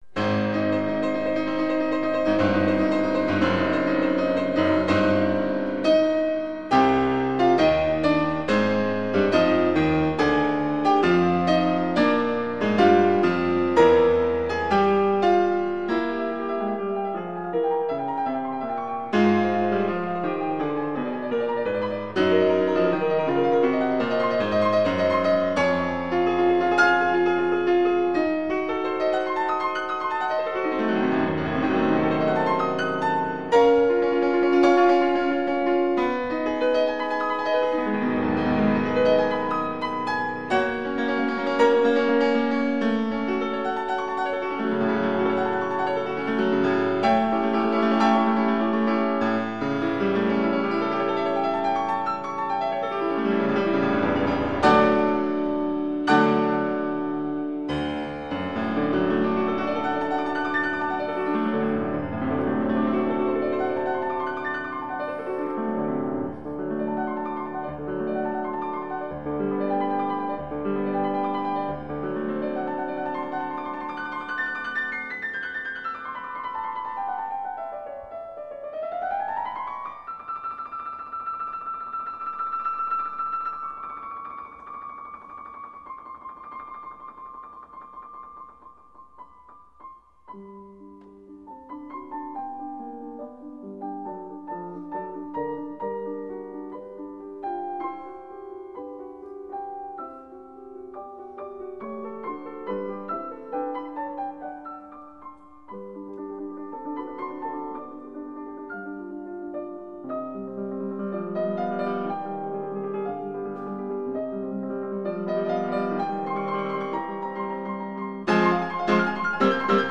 Incontro
Ma i nostri ascolti iniziano con la cadenza scritta dallo stesso Beethoven per il suo Terzo Concerto , nell'interpretazione di Krystian Zimerman, con i Wiener Philharmoniker diretti da Leonard Bernstein.